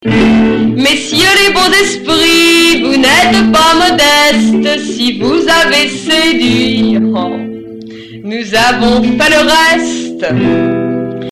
Archives d'époque à la guitare